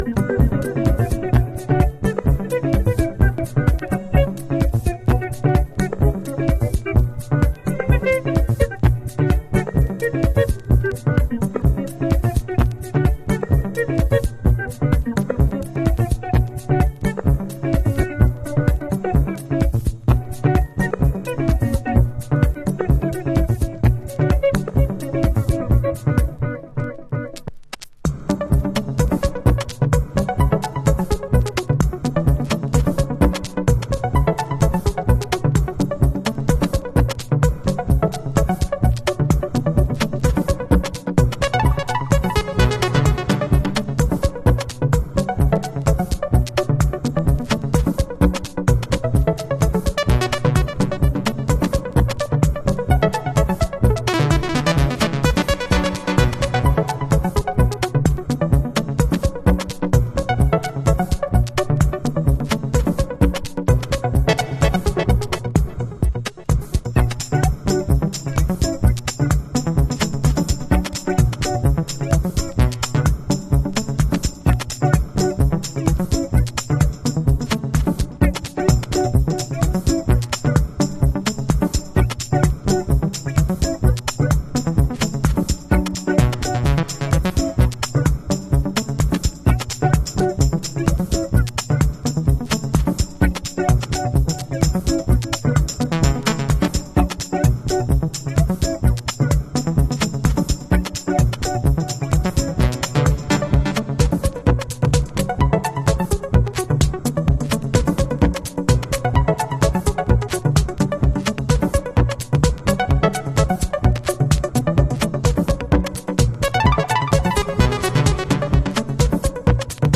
House / Techno
ねっとりドラムプログラミングにシンセとギターがエフェクティブに絡み合う、ラテンテイストのクリックハウス。